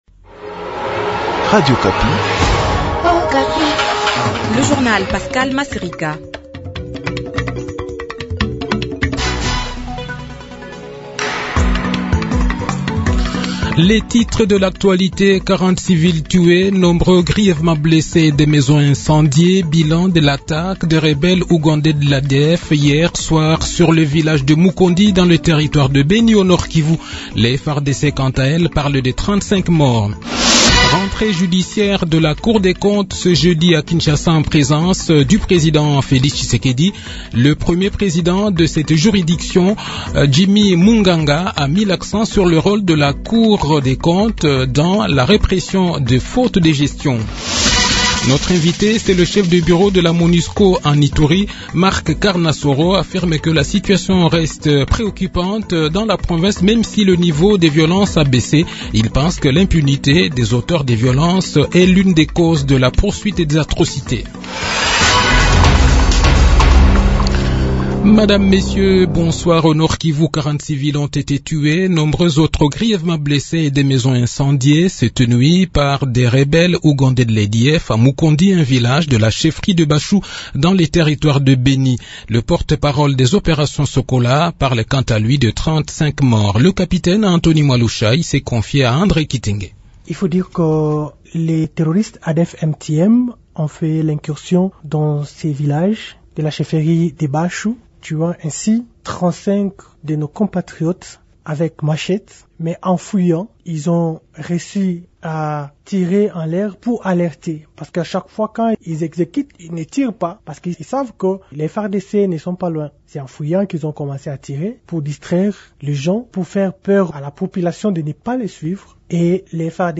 Le journal de 18 h, 9 mars 2023